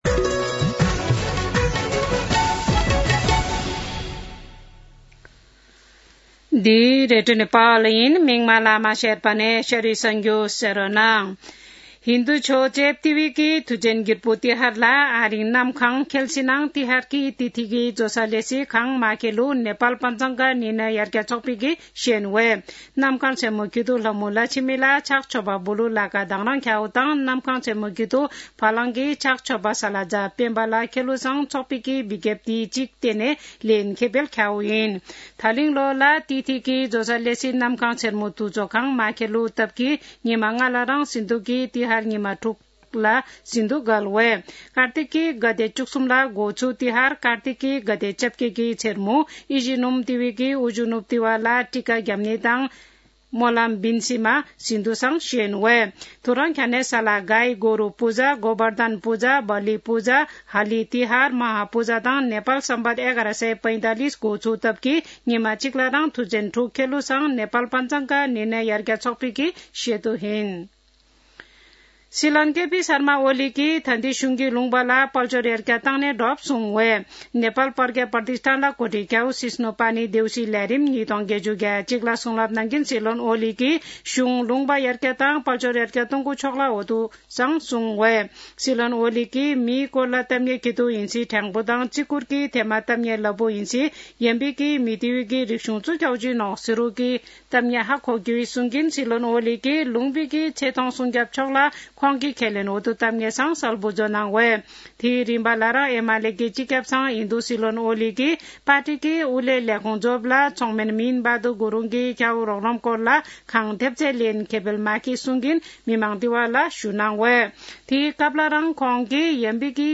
शेर्पा भाषाको समाचार : १७ कार्तिक , २०८१